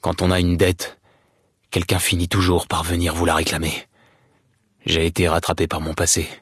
Dialogue audio de Fallout: New Vegas